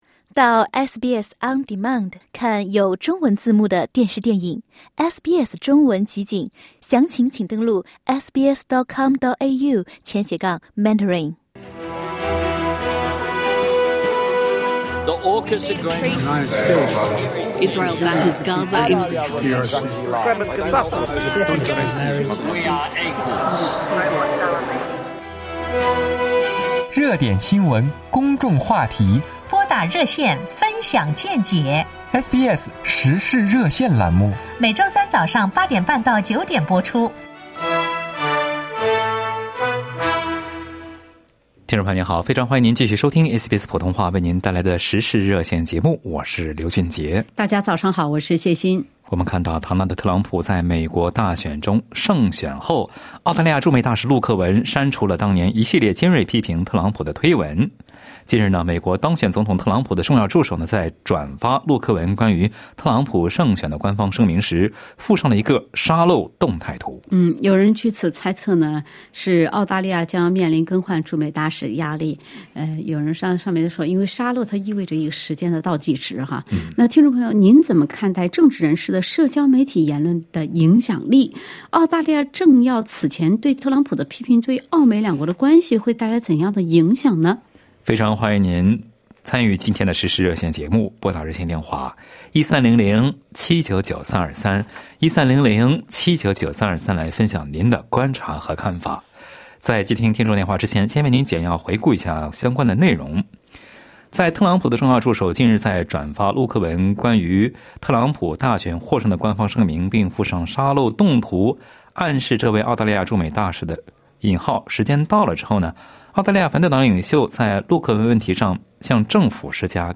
澳洲政要此前对特朗普的批评会影响澳美两国关吗？SBS普通话《时事热线》节目听友表达了看法。